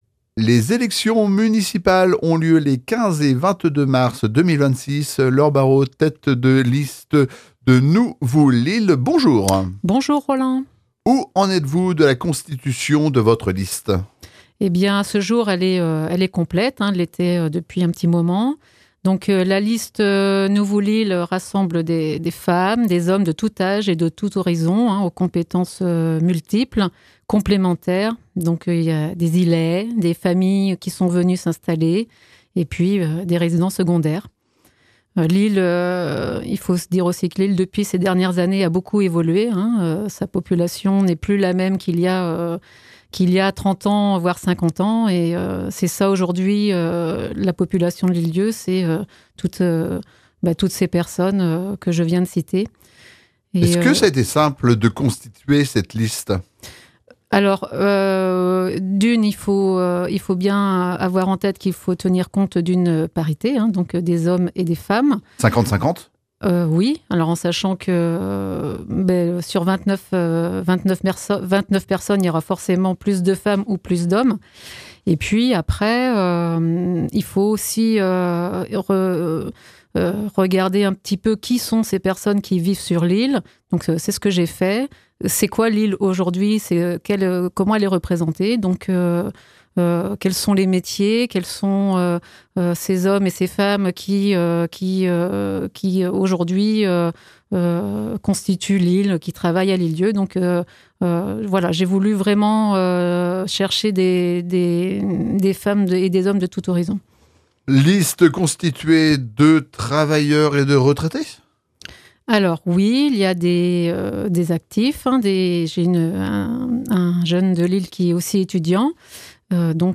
Nous poursuivons notre série d’interviews consacrées aux élections municipales des 15 et 22 mars 2026 à l’Île d’Yeu.